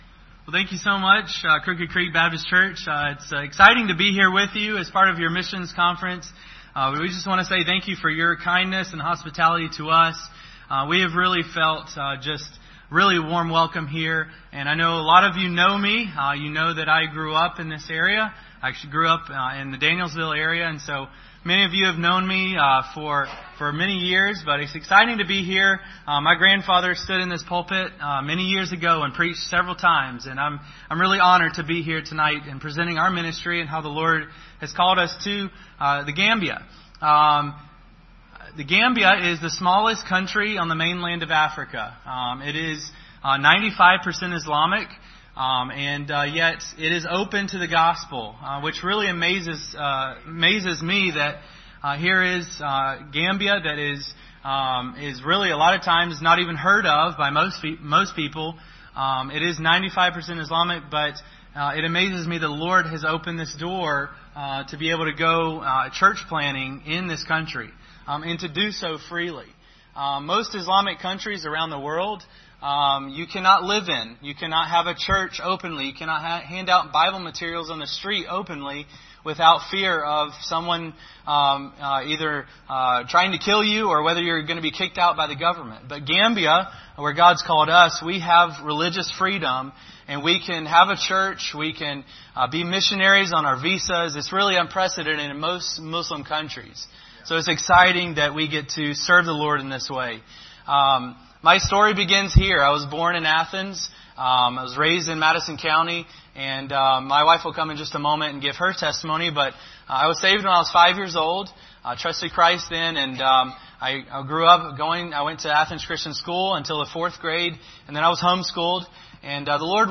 Series: 2018 Missions Conference
Service Type: Special Service